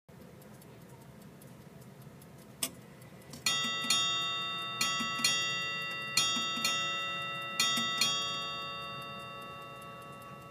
The bells would add one chime every ½ hour. Once it strikes 8 bells, the shift changes and the bells start again at 1 chime. Here’s a short clip of how the Ship’s Bell clock sounds:
Ships-Bell.mp3